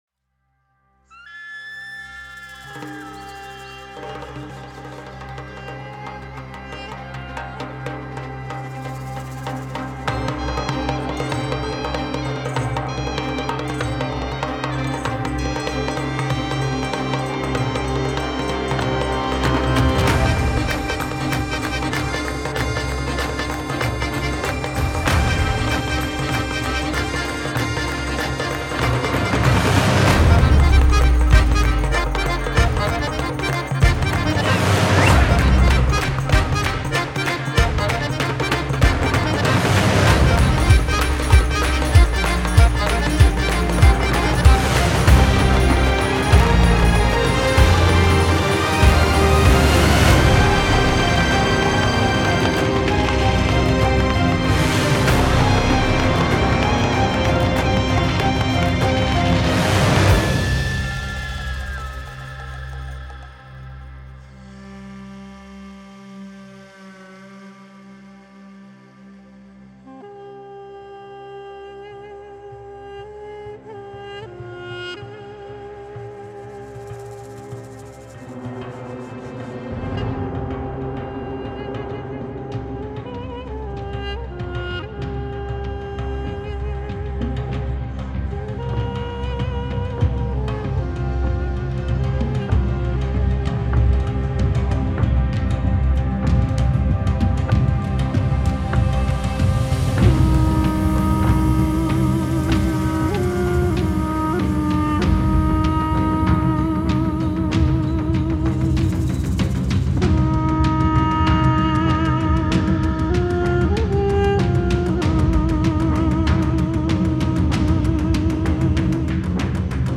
Музыка
Мощная, быстрая, стремительная — как сама битва.